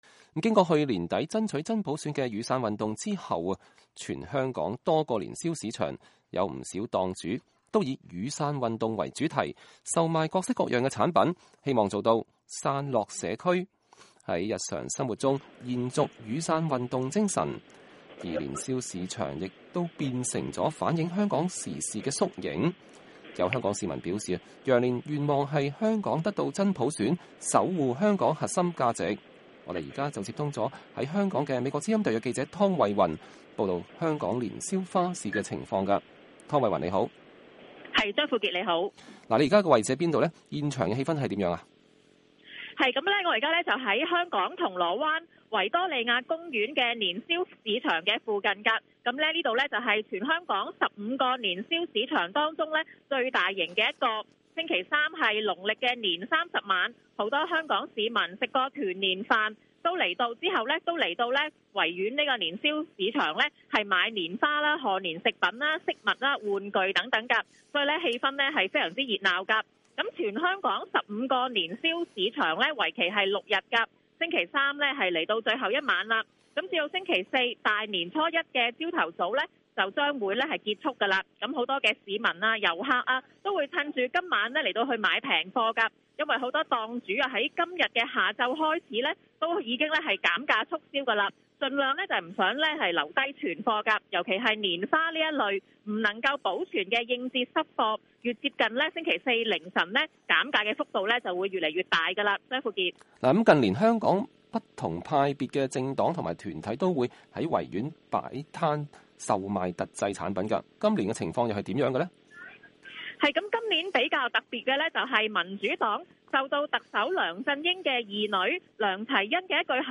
現場報導-香港年宵花市 多見「傘落社區」